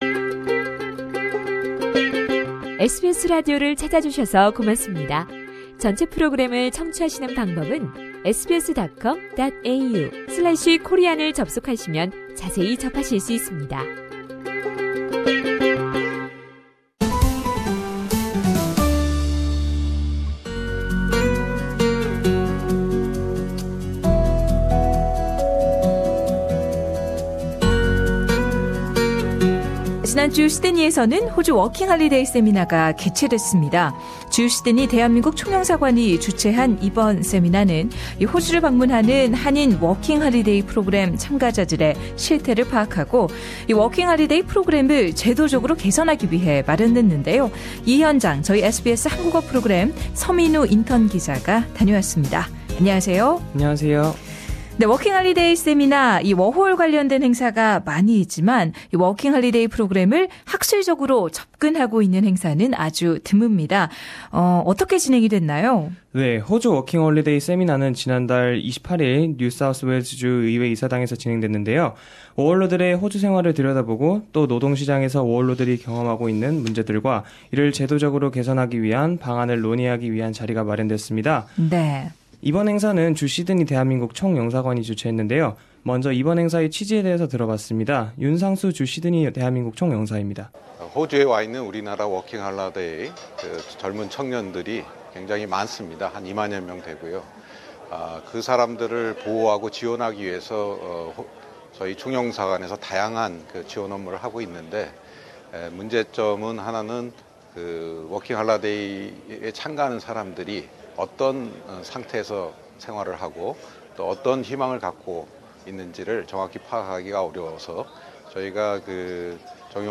Seminar on Australian Working Holiday Program
A seminar takes an academic approach to Australian Working Holiday program was hosted by the Consulate General of the Republic of Korea in Sydney.